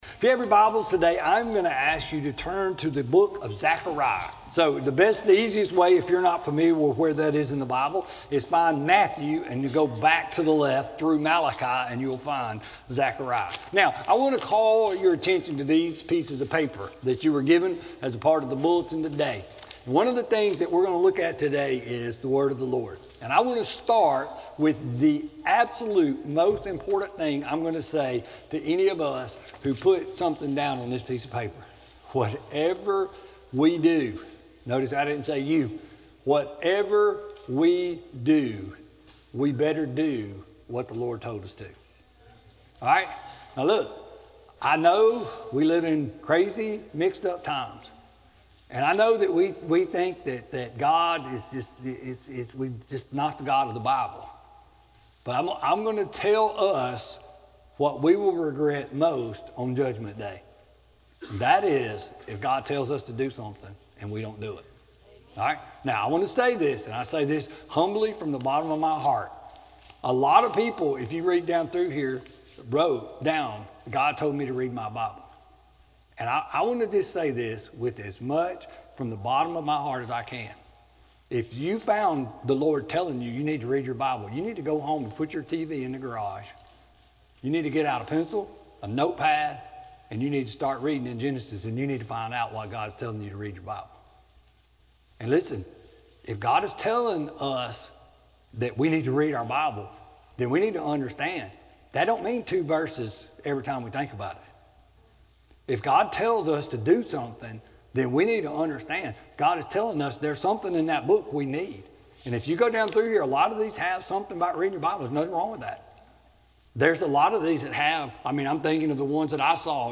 Sermon-2-17-19-CD.mp3